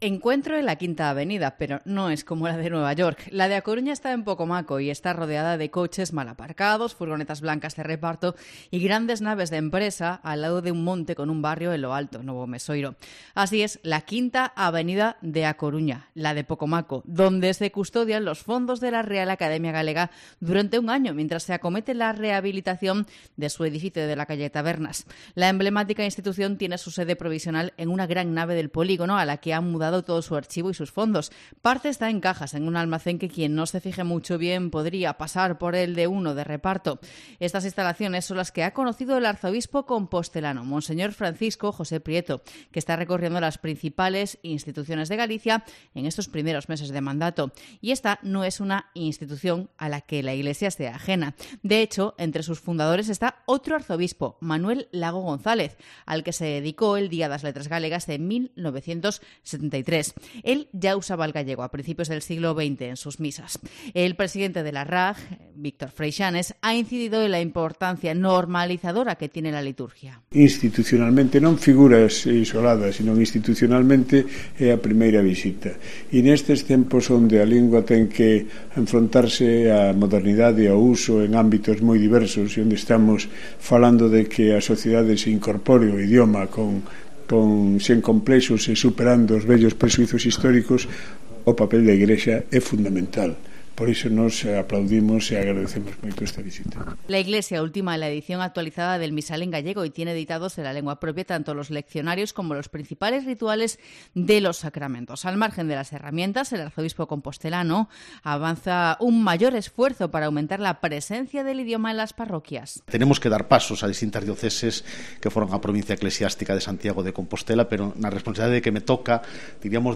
Crónica de la visita del Arzobispo de Santiago a la Real Academia Galega